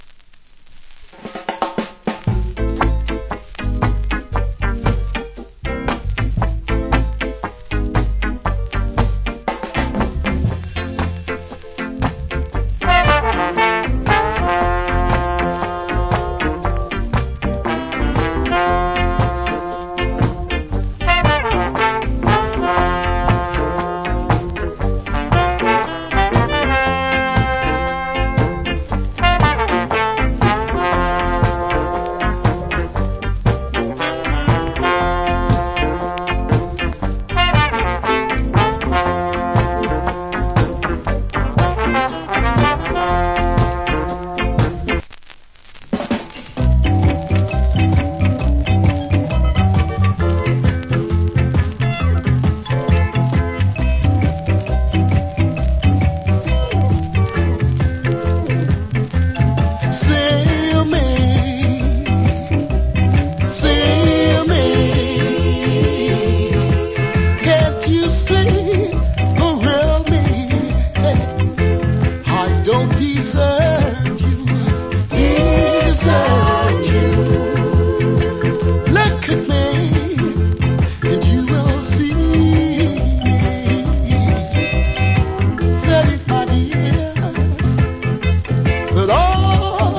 Cool Early Reggae Inst.